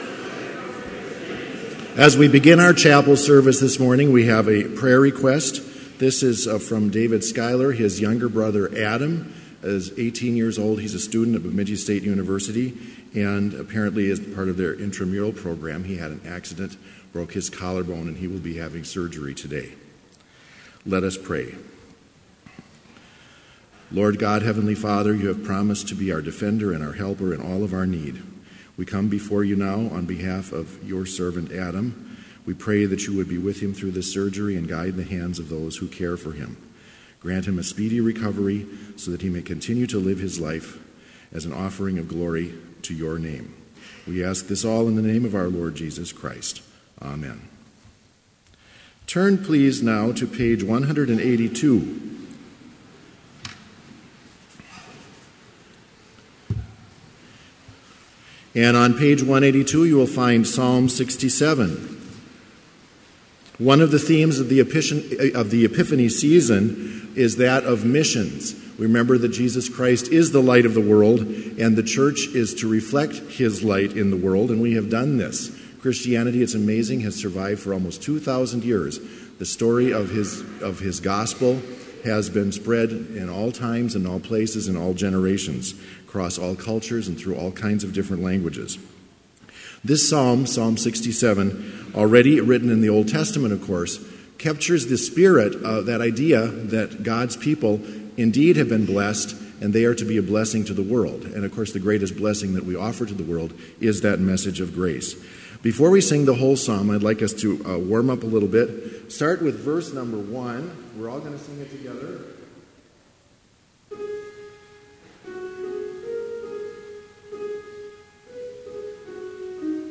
Complete service audio for Chapel - January 26, 2017